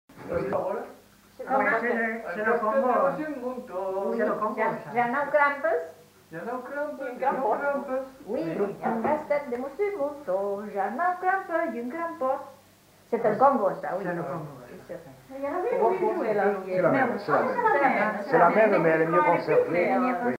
Lieu : Saint-Gor
Genre : chant
Type de voix : voix de femme ; voix d'homme
Production du son : chanté
Danse : congo